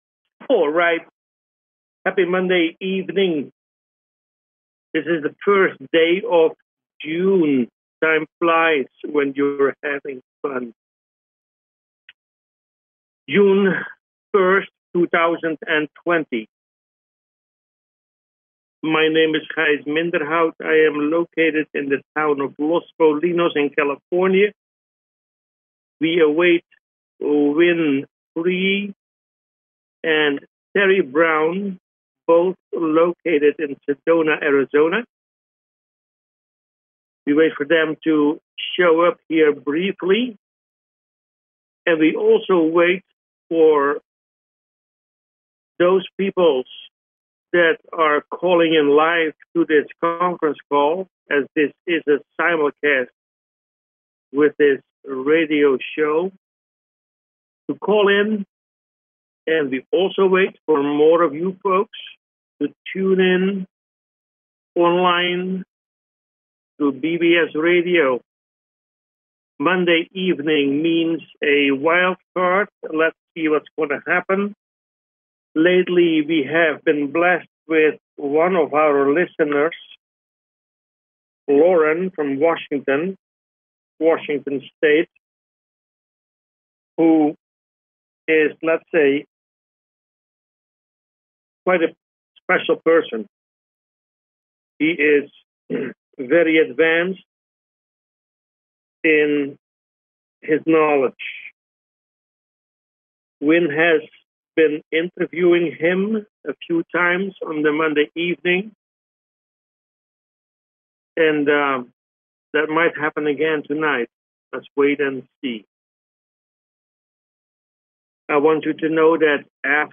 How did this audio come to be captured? Conference Call LIVE